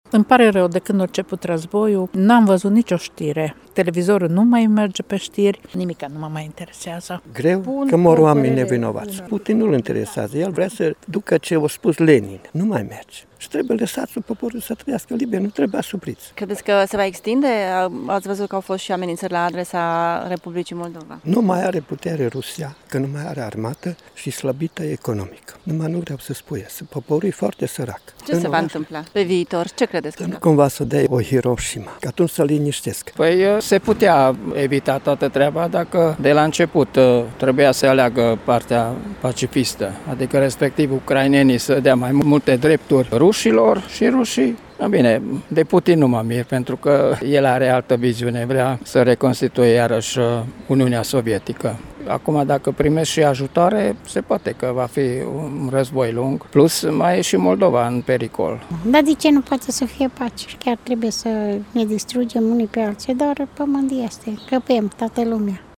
Mâine se împlinește un an de când Rusia a început agresiunea asupra Ucrainei. Unii târgumureșeni cred că nu s-ar fi ajuns la conflict dacă ar fi existat, dorința de pace: